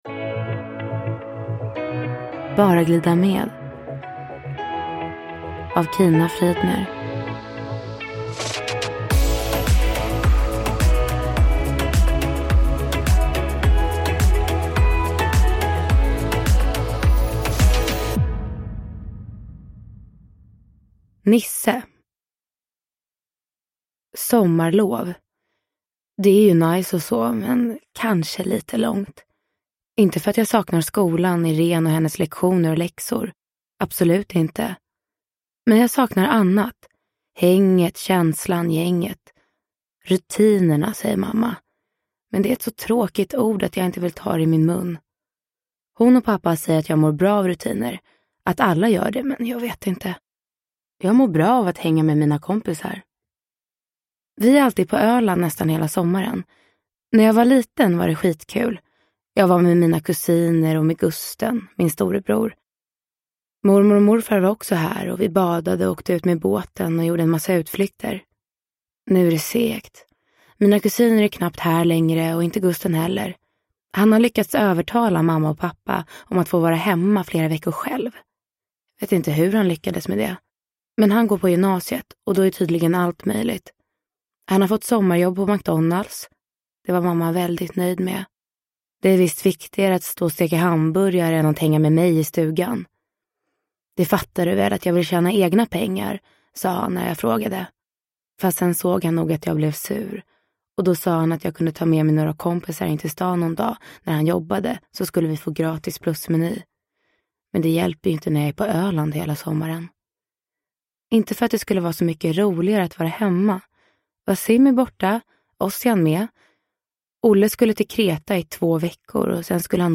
Bara glida med – Ljudbok – Laddas ner